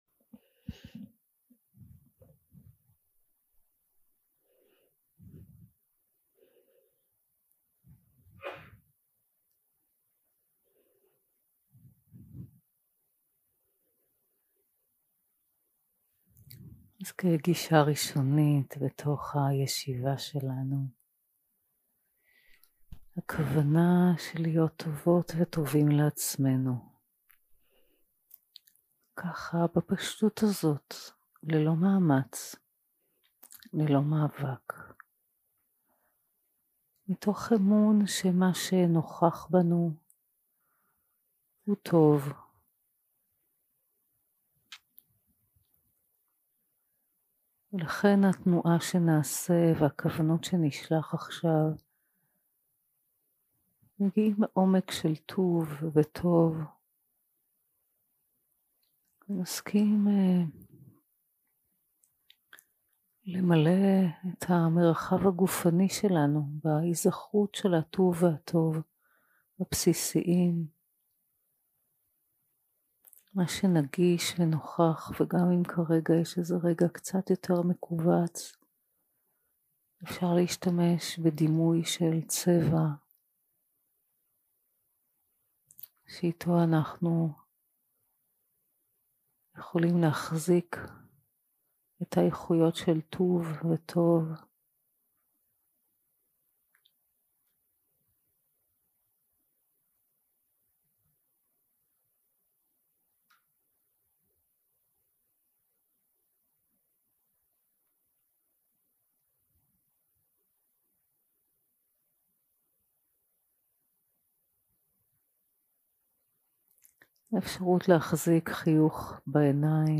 יום 7 – הקלטה 18 – צהריים – מדיטציה מונחית - כל היצורים החיים 0:00 0:00 סוג ההקלטה: Dharma type: Guided meditation שפת ההקלטה: Dharma talk language: Hebrew